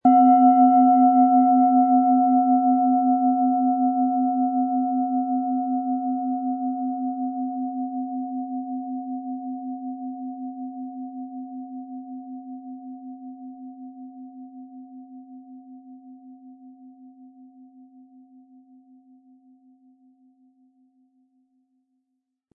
Handgearbeitete tibetische Schale mit dem Planetenton Delfin.
Sie möchten den schönen Klang dieser Schale hören? Spielen Sie bitte den Originalklang im Sound-Player - Jetzt reinhören ab.
Ein die Schale gut klingend lassender Schlegel liegt kostenfrei bei, er lässt die Planetenklangschale Delfin harmonisch und angenehm ertönen.
MaterialBronze